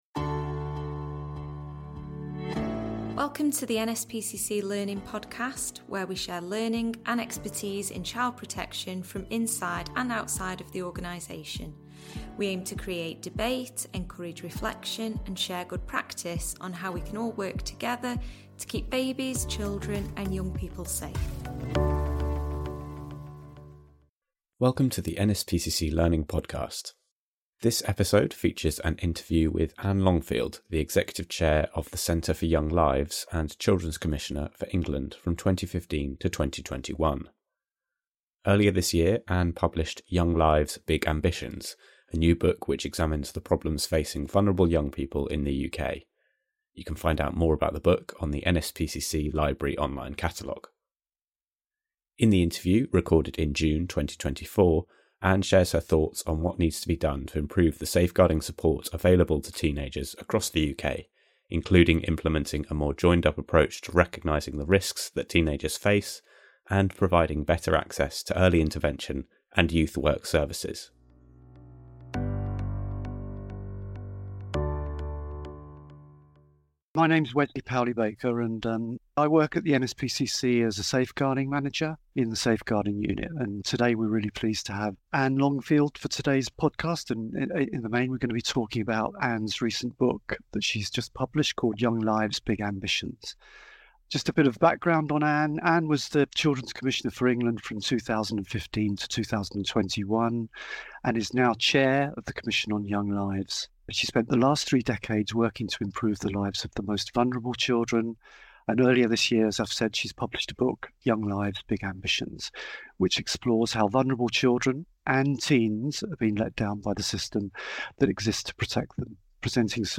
Providing better support for teenagers — an interview with Anne Longfield